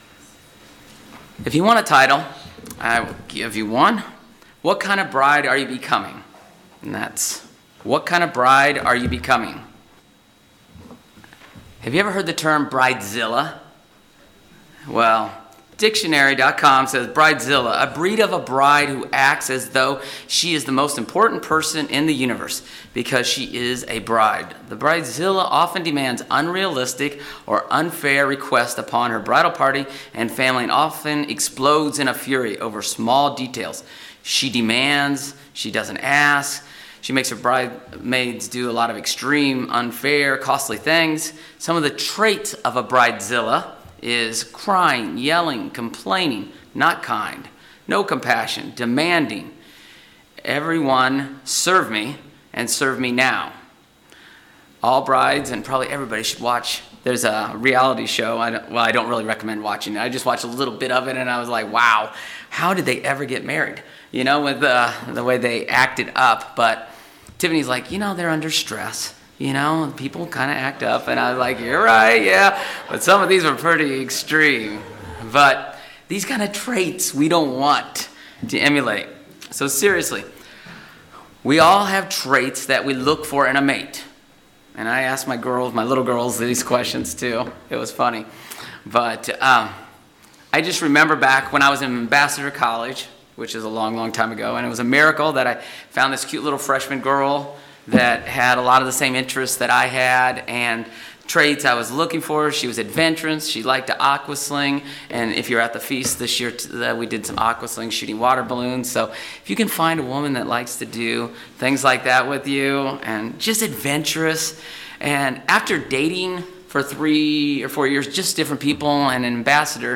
Just as we look for a mate with certain qualities, Christ is also looking for a bride (the church) with specific traits. This sermon looks at the betrothal process and Proverbs 31 to find traits we should be building now.